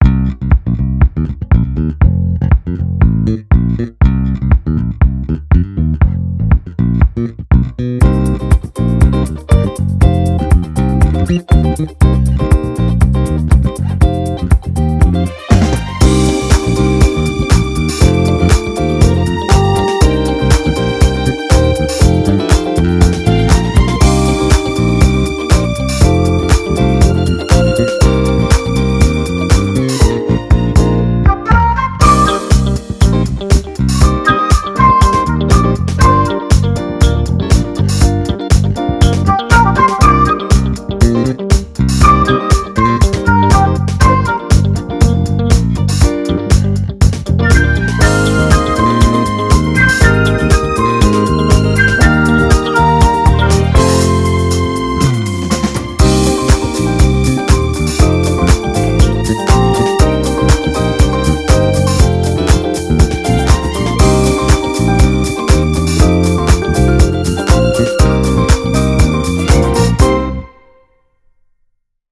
Fat, mix-ready low end